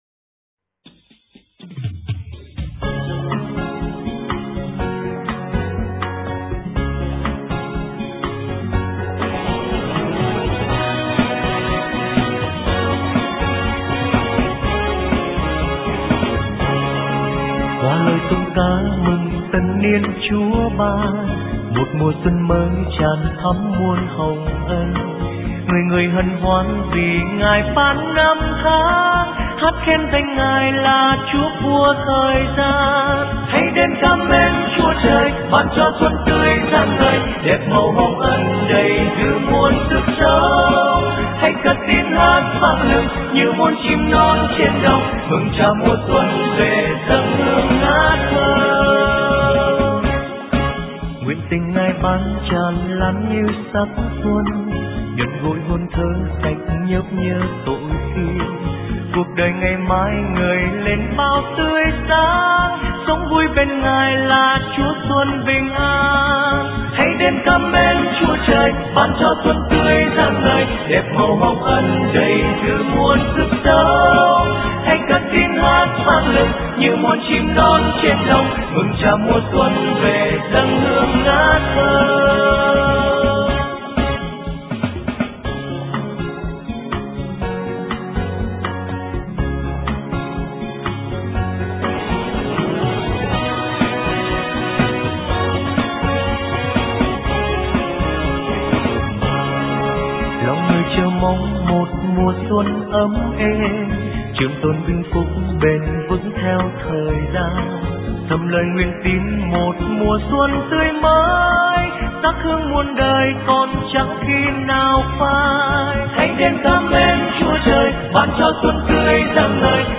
* Thể loại: Mừng Xuân